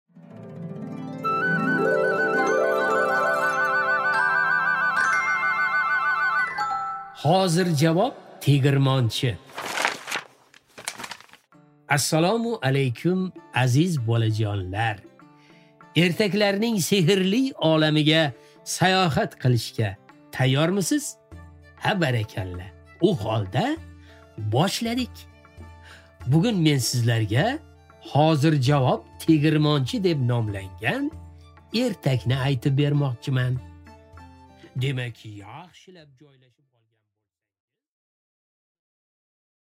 Аудиокнига Hozirjavob tegirmonchi